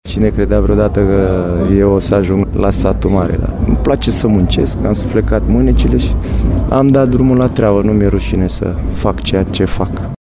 Lobonț spune că nu a ezitat să se alăture proiectului, când a venit oferta din liga a treia, de la Satu Mare: